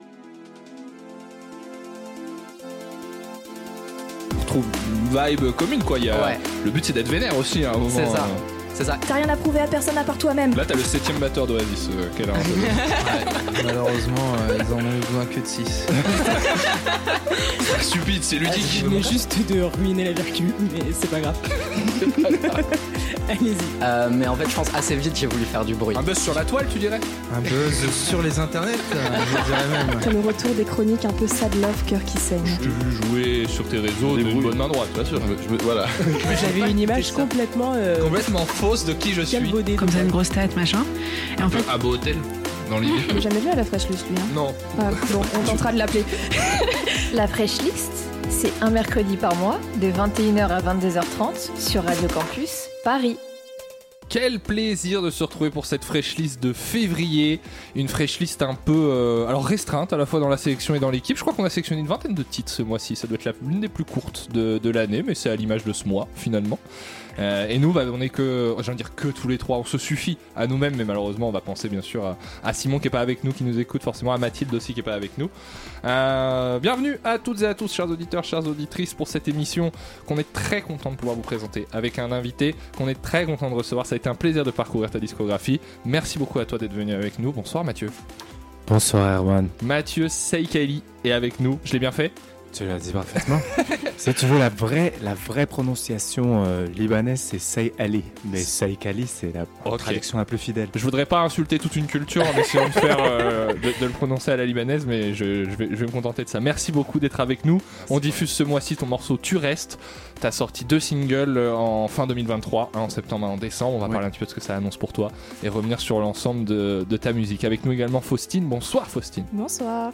La Fraîche Liste est de retour pour une nouvelle saison en direct !